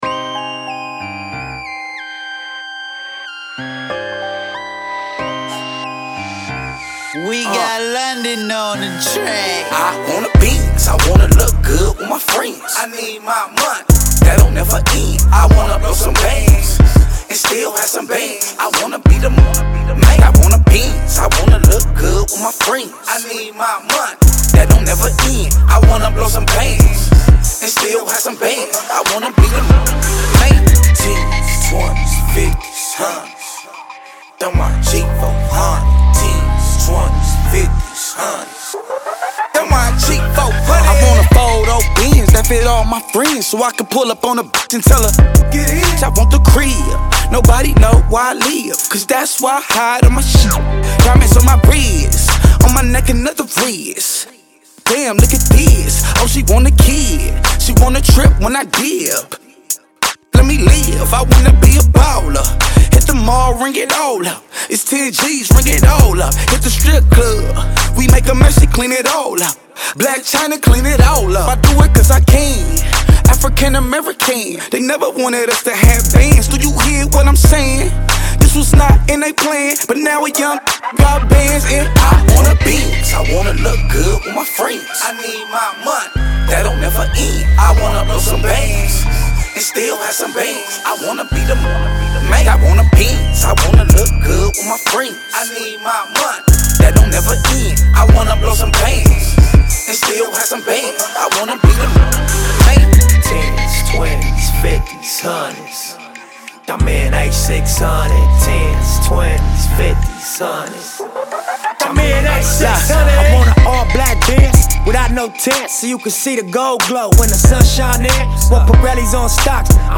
DROPS HIS NEW WEST COAST BANGER